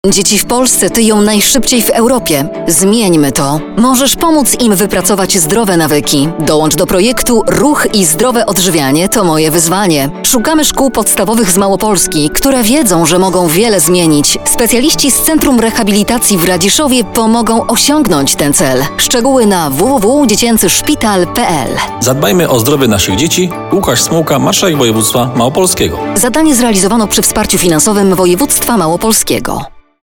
Spot radiowy dla szkół
Szpila_dzieciecy_Projekt_Ruch_i_zdrowe_odżywianie_to_moje_wyzwanie_v1.mp3